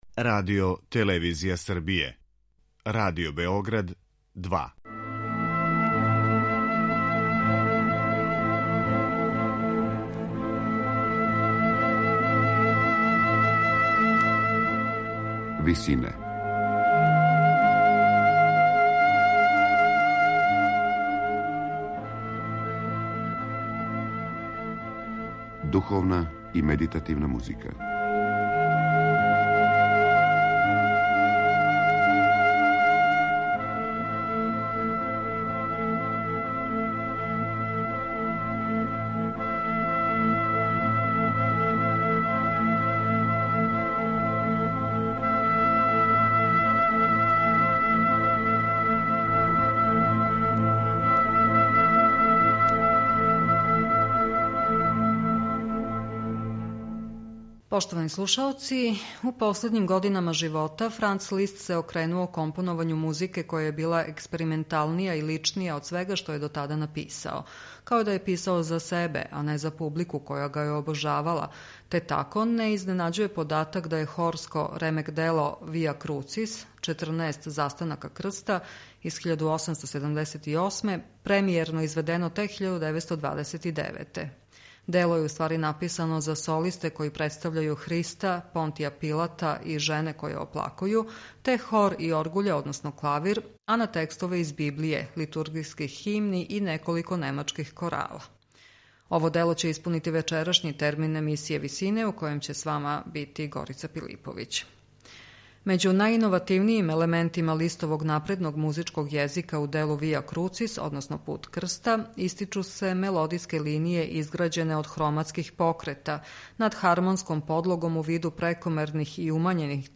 Дело је написано за солисте који представљају Христа, Понтија Пилата и жене које оплакују, те хор и оргуље односно клавир, а на текстове из Библије, литургијских химни и неколико немачких корала.